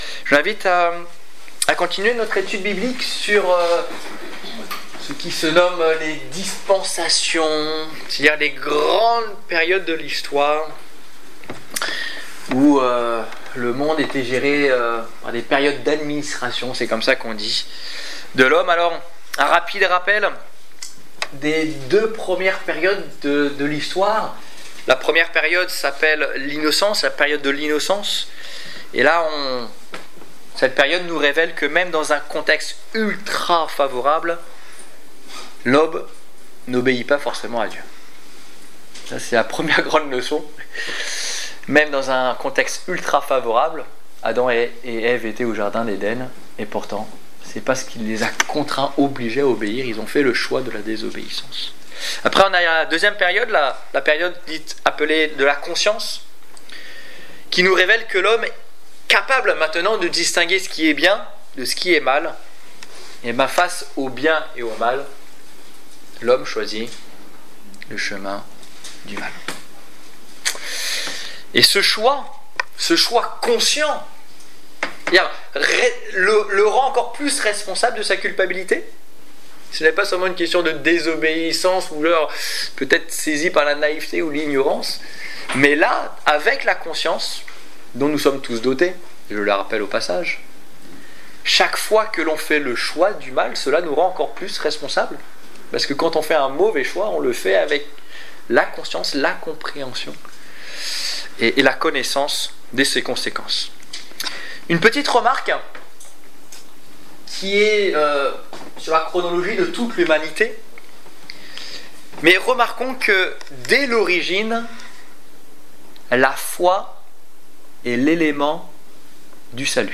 Étude biblique